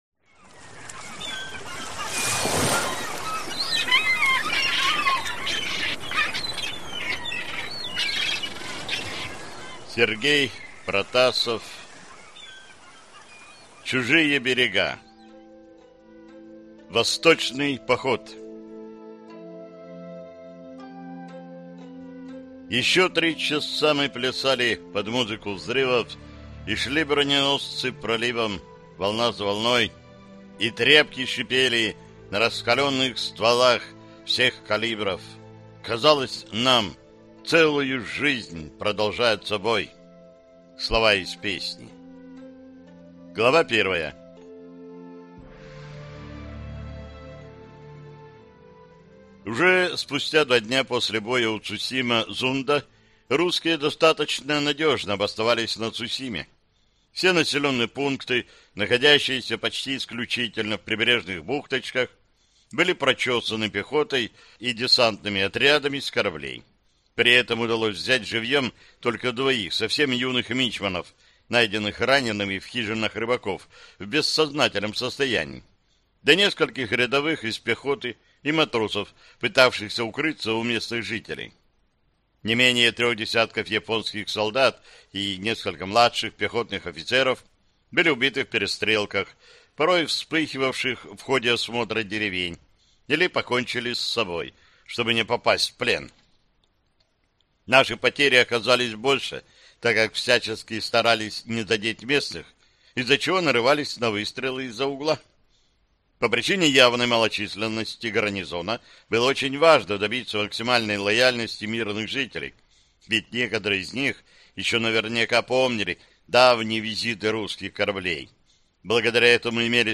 Аудиокнига Цусимские хроники. Чужие берега | Библиотека аудиокниг